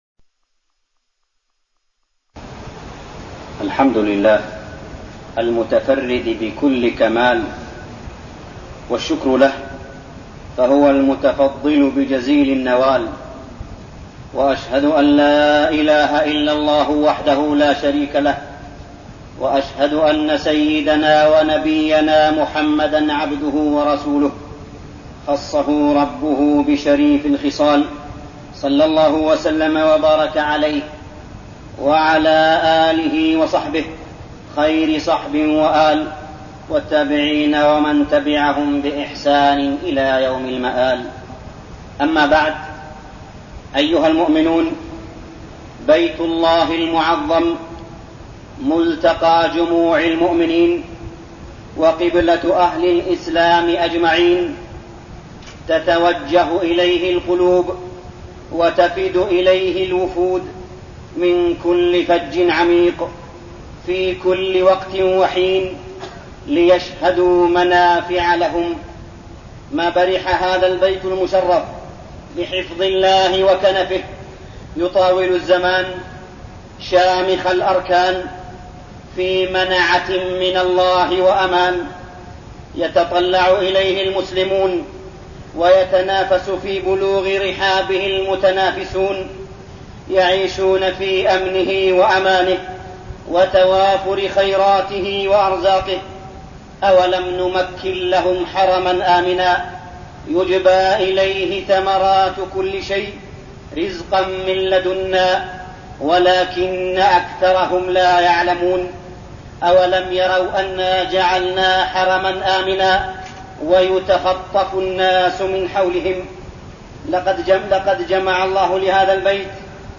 خطبة الجمعة 7-3-1410هـ > خطب الحرم المكي عام 1410 🕋 > خطب الحرم المكي 🕋 > المزيد - تلاوات الحرمين